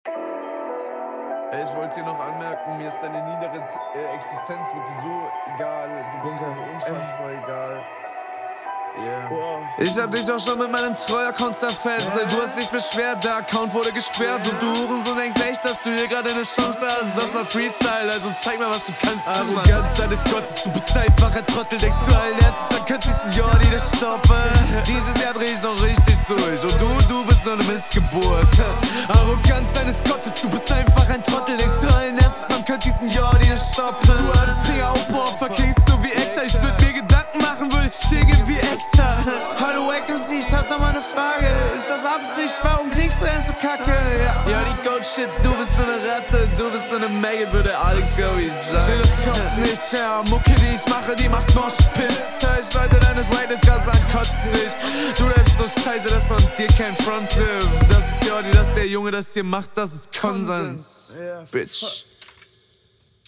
Klingst als hättest du mindestens 3 Valium drin, kp ob das auf was anspielt, ich …